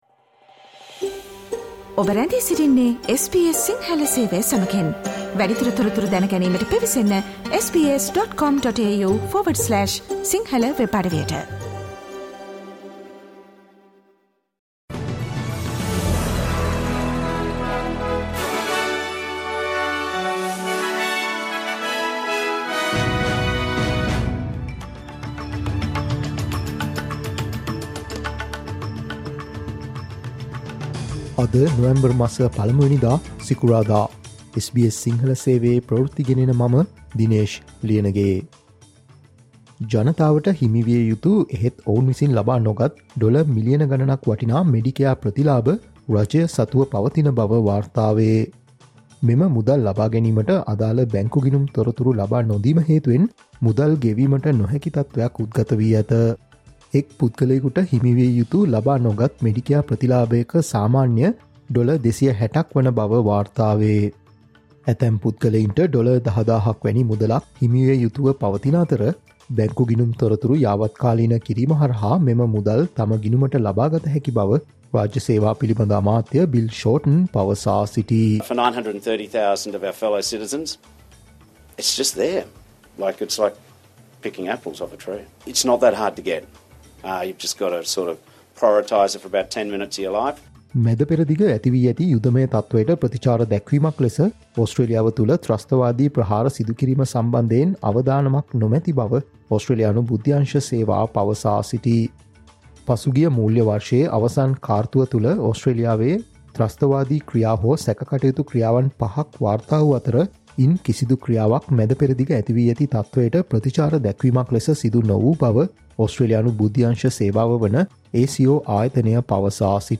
Australia's news in Sinhala.